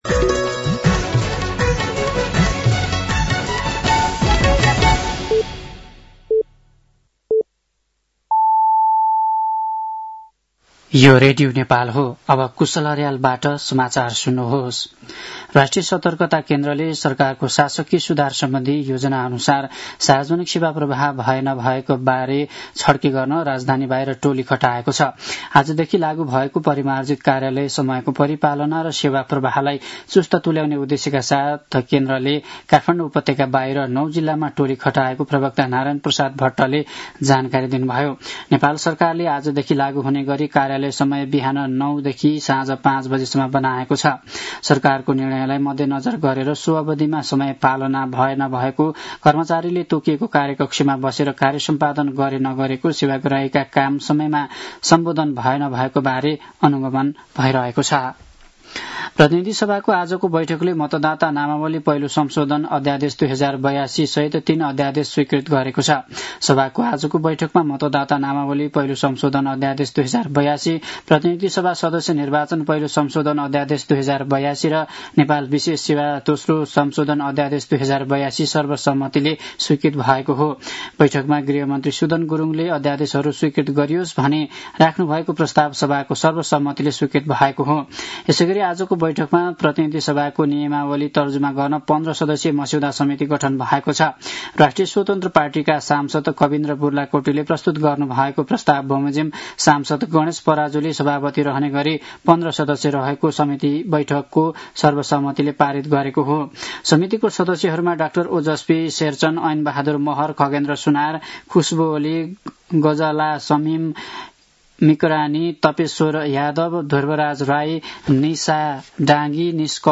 साँझ ५ बजेको नेपाली समाचार : २३ चैत , २०८२
5.-pm-nepali-news-.mp3